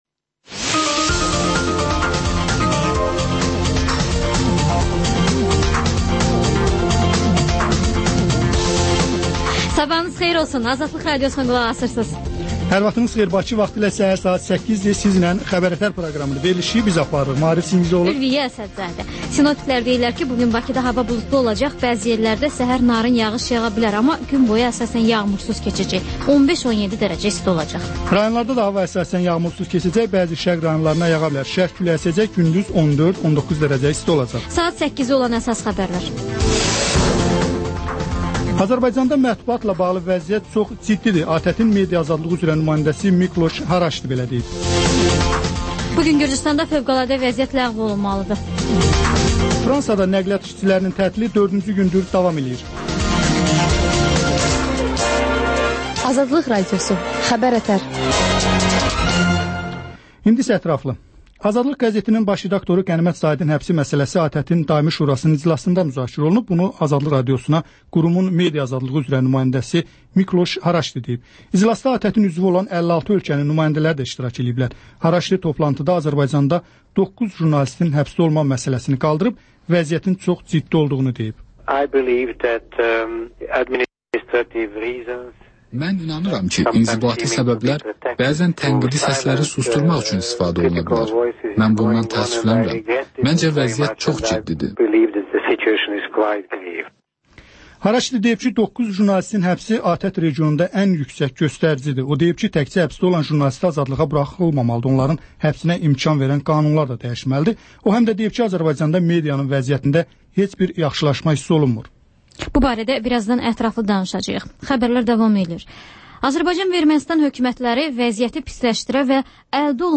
Xəbər-ətər: xəbərlər, müsahibələr və TANINMIŞLAR verilişi: Ölkənin tanınmış simalarıyla söhbət